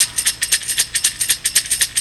TAMBOU 2  -R.wav